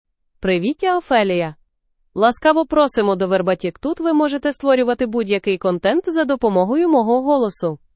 Ophelia — Female Ukrainian AI voice
Ophelia is a female AI voice for Ukrainian (Ukraine).
Voice sample
Listen to Ophelia's female Ukrainian voice.
Female